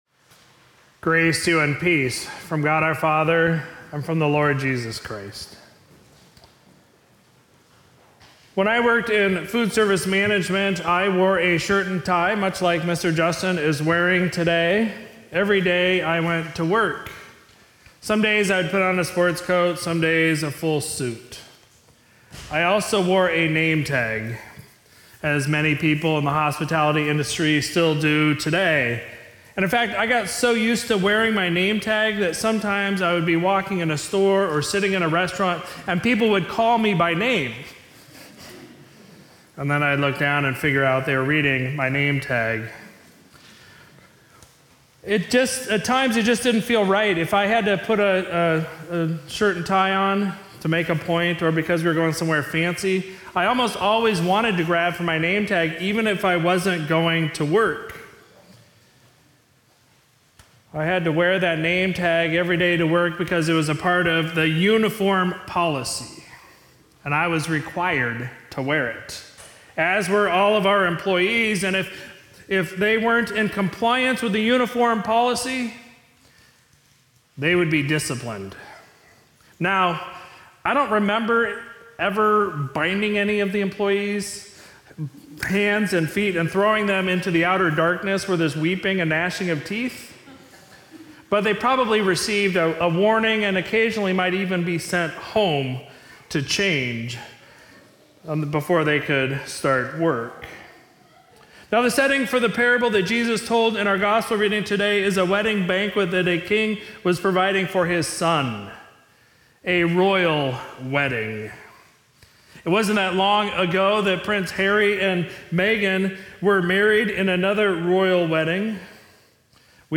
Sermon for Sunday, March 12, 2023